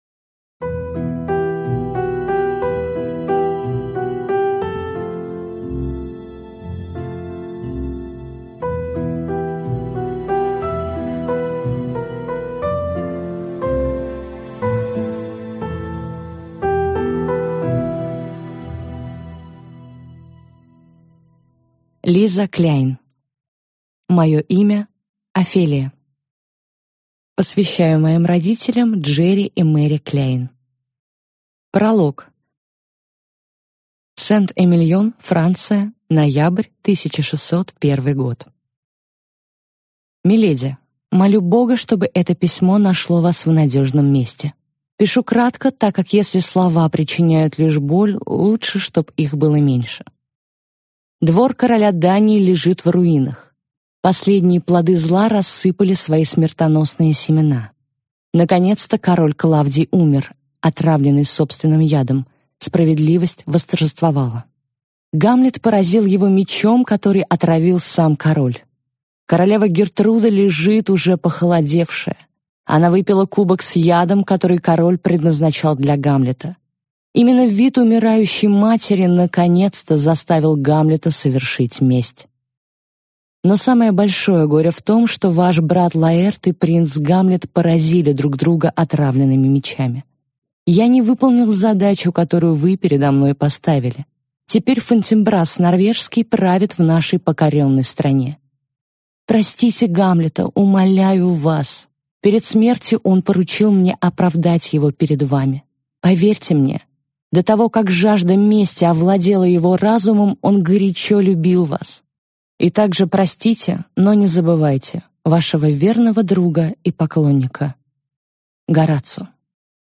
Аудиокнига Мое имя Офелия | Библиотека аудиокниг